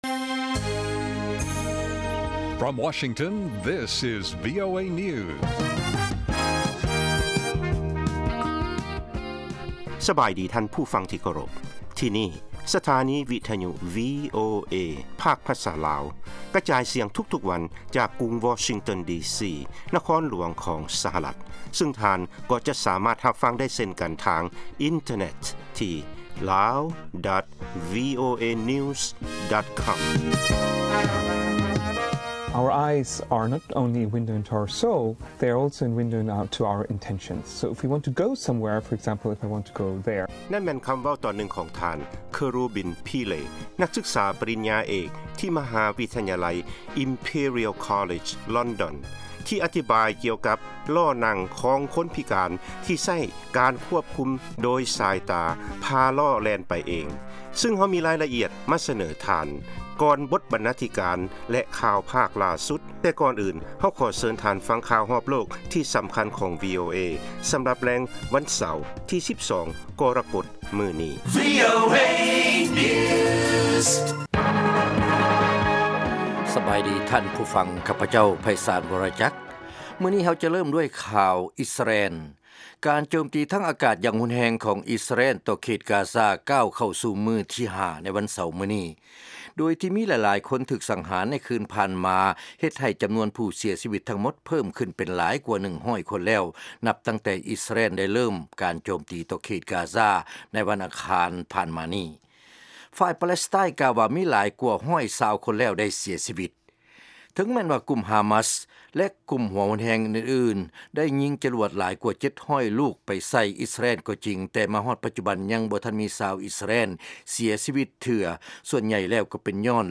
ລາຍການກະຈາຍສຽງຂອງວີໂອເອ ລາວ
ວີໂອເອພາກພາສາລາວ ກະຈາຍສຽງທຸກໆວັນ ເປັນເວລາ 30 ນາທີ.